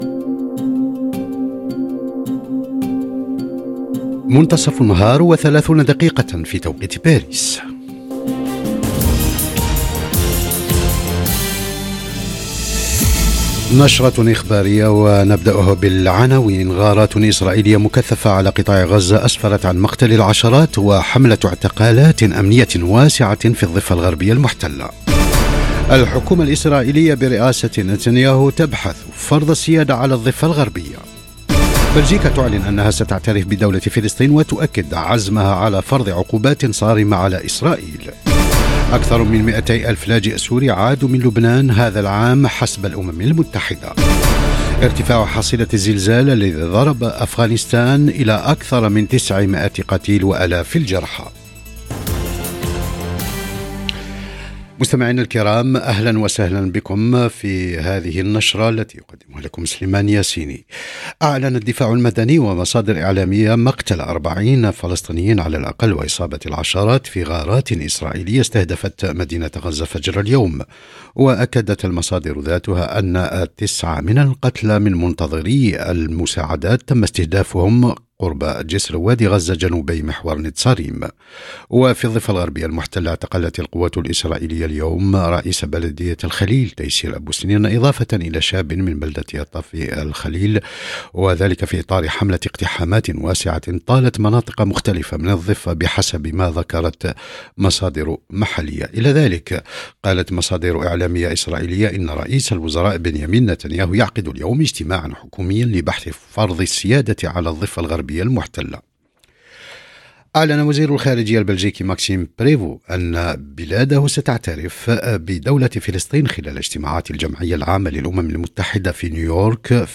نشرة أخبار الظهيرة: تصاعد الأزمات في الشرق الأوسط وأفغانستان بين صراعات سياسية وكوارث إنسانية - Radio ORIENT، إذاعة الشرق من باريس